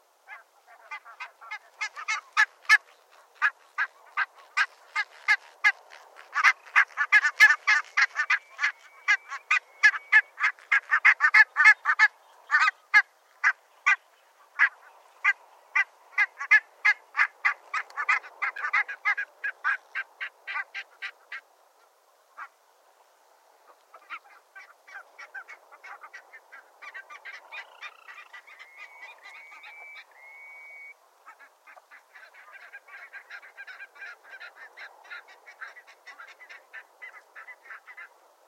Barnacle goose
Their call is a repeated single-tone barking.